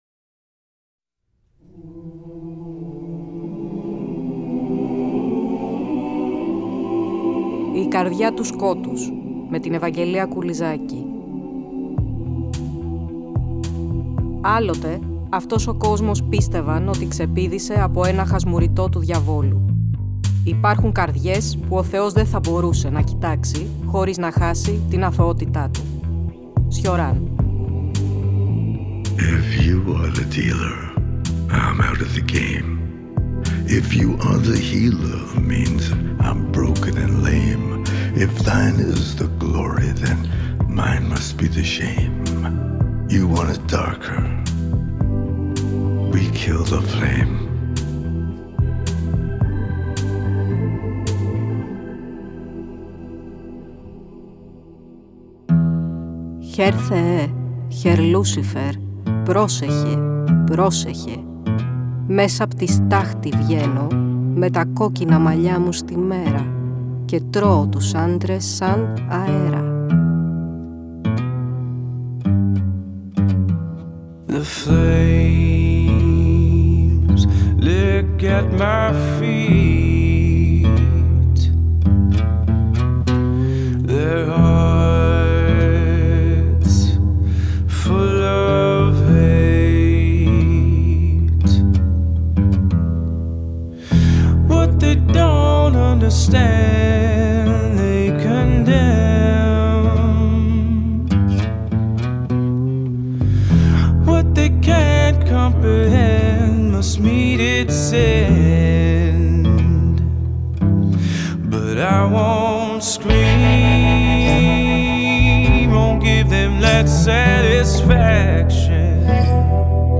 Ακούστε το 19ο επεισόδιο του τρέχοντος Κύκλου της εκπομπής, που μεταδόθηκε την Κυριακή 01 Φεβρουαρίου από το Τρίτο Πρόγραμμα.